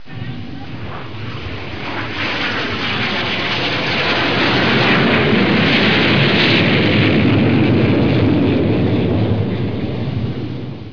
دانلود آهنگ طیاره 33 از افکت صوتی حمل و نقل
جلوه های صوتی
دانلود صدای طیاره 33 از ساعد نیوز با لینک مستقیم و کیفیت بالا